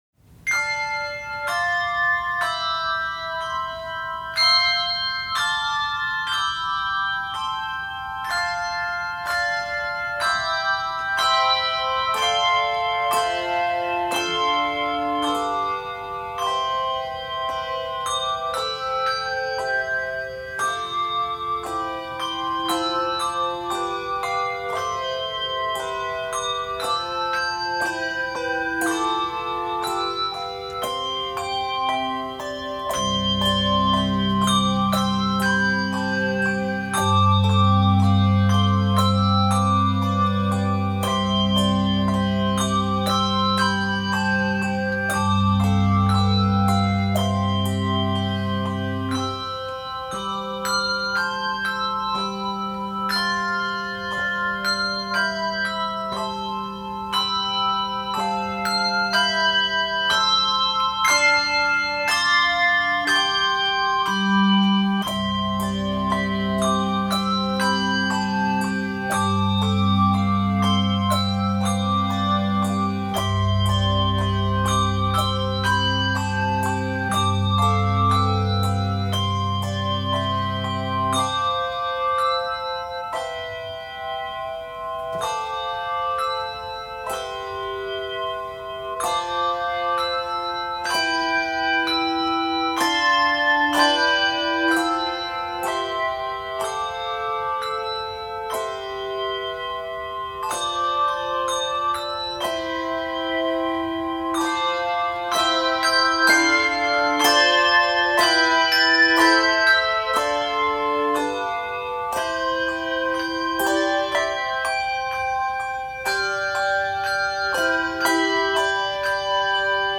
for 3-5 octaves with optional low bass chimes
Key of C Major. 53 measures.